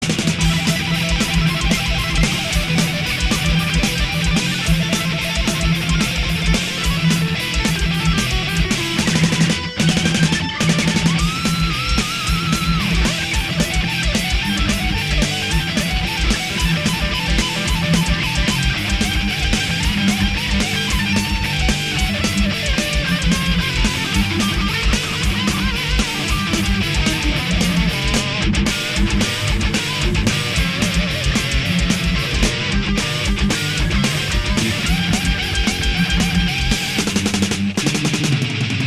guitar solos